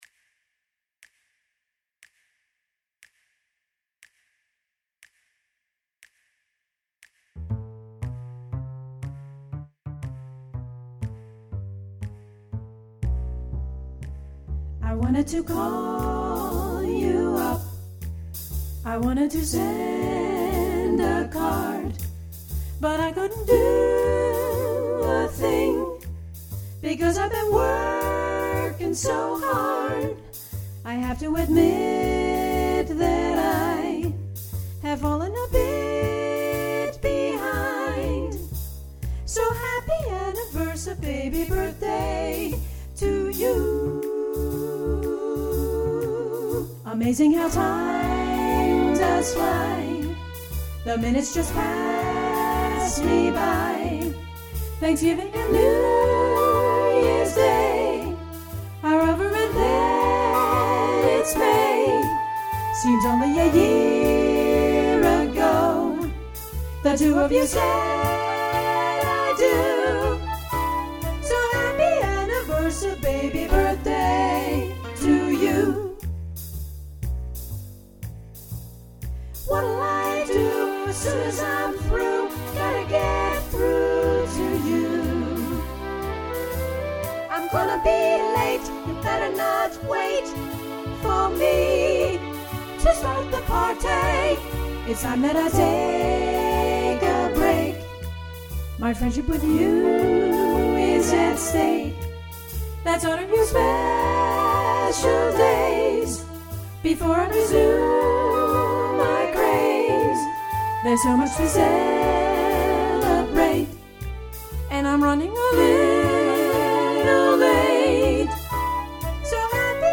40’s Midtempo